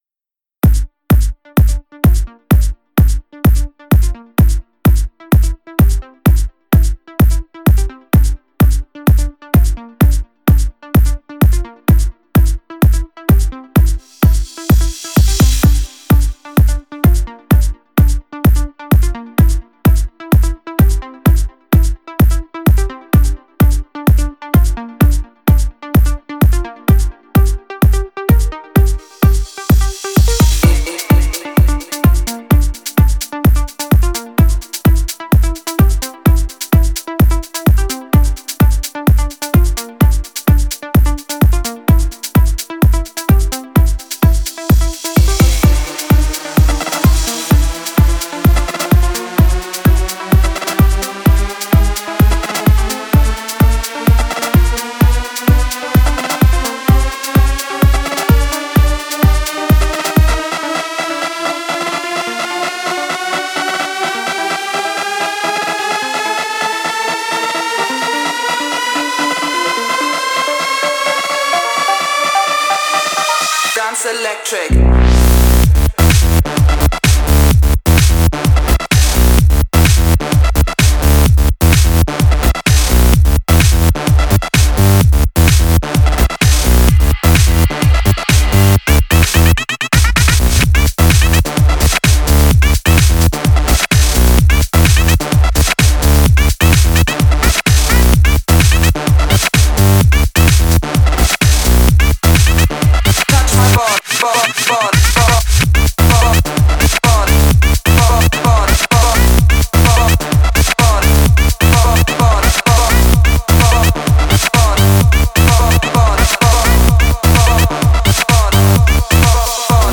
Жанр:House